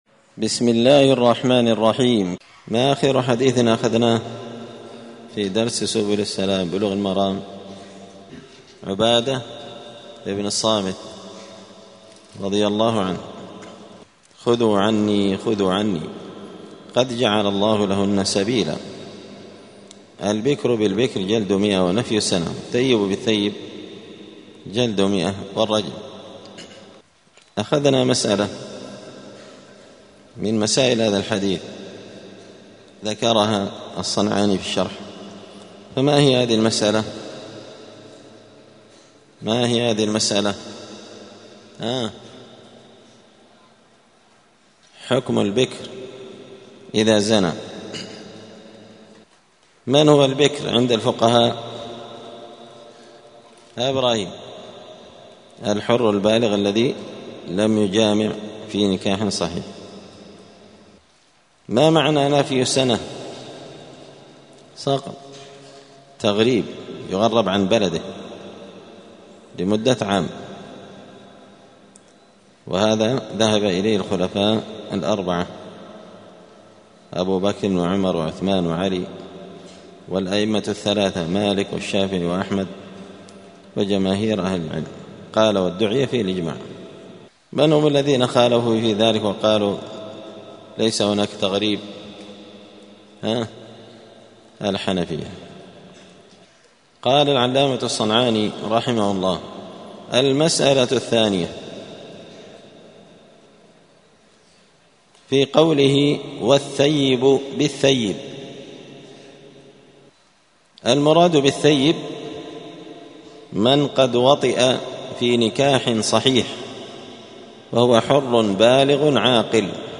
*الدرس الثالث (3) {باب الإقرار المعتبر في الزنا}*